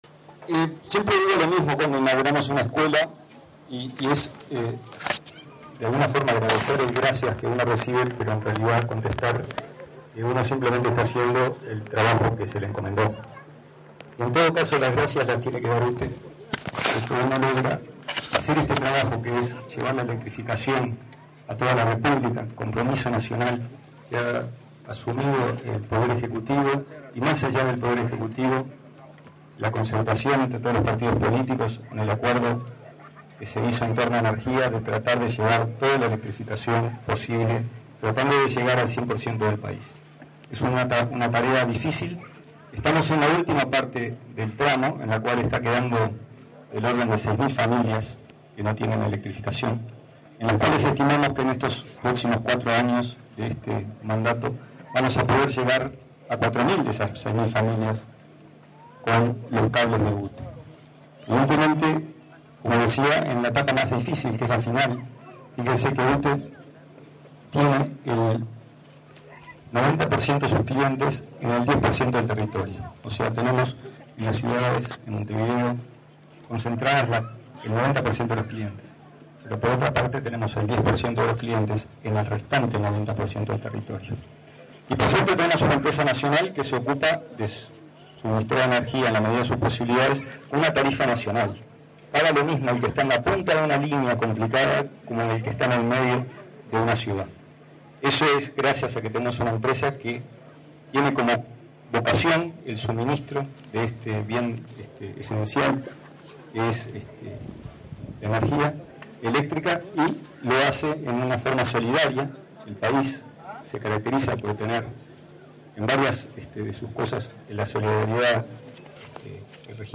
Palabras del presidente de UTE, Gonzalo Casaravilla, en la inauguración del tendido eléctrico en Rincón de Rodríguez